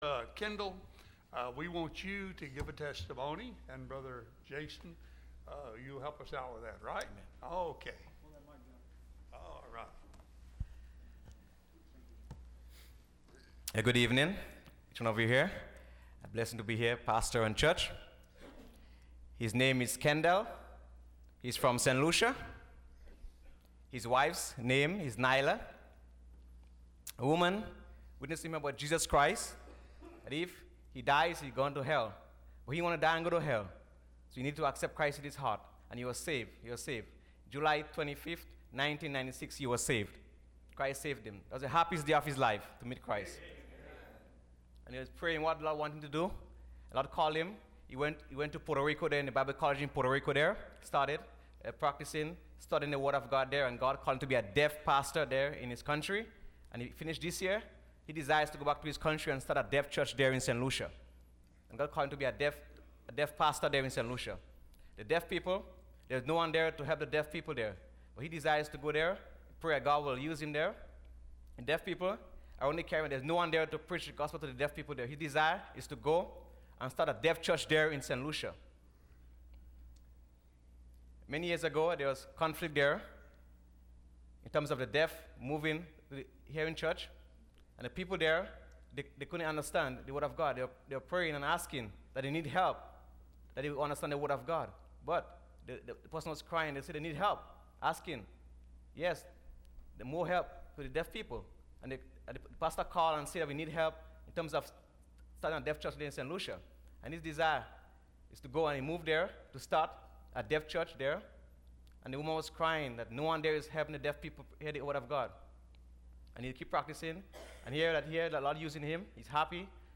Testimony – Landmark Baptist Church
Service Type: Missions Conference